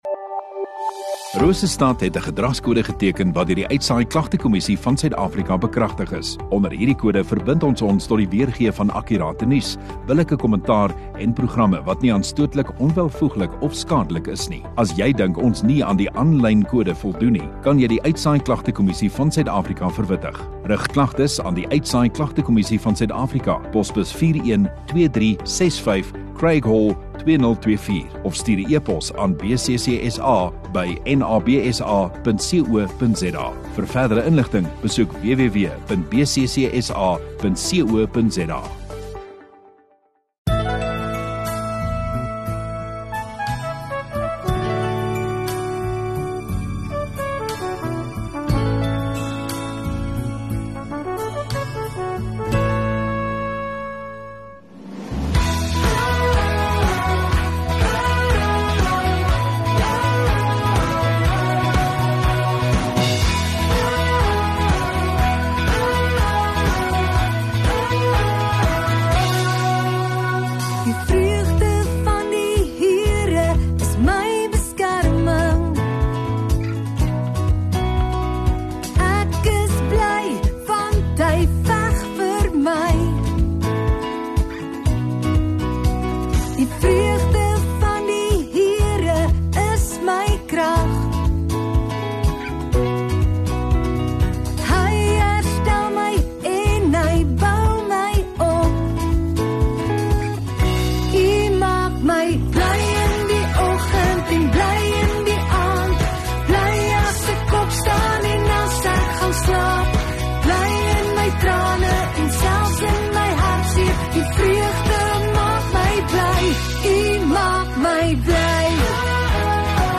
2 Jan Donderdag Oggenddiens